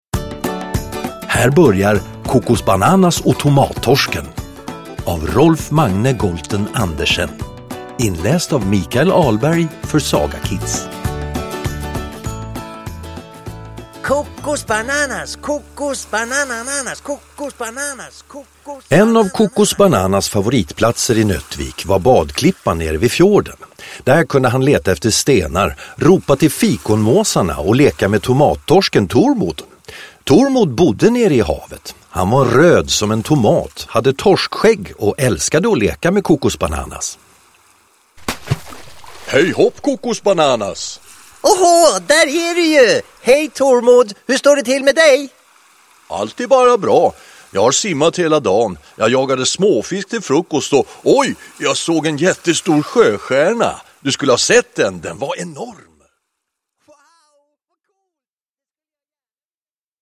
Ljudbok
Med härliga ljudeffekter och musik bjuder Kokosbananas på underhållning för hela familjen!